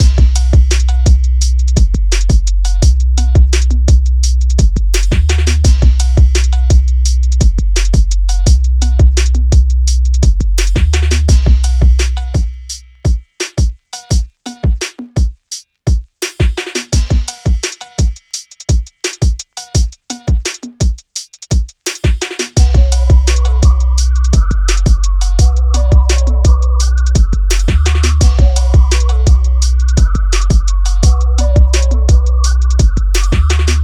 This compact pack delivers 17 meticulously processed 808 drum sounds + 1 ready-to-use drum loop — everything you need for that signature booming low-end and crisp rhythm.
Handcrafted with a focus on clarity and power, these are clean, studio-ready 808s that cut through the mix effortlessly. No muddy frequencies, no unnecessary extras — just the essential classic 808 tones done right: deep subs, tight kicks, snapping snares, slick hi-hats, and all the percussive elements that make your beats hit hard and sound professional.
DRUMKIT-808-Essentails-Preview.wav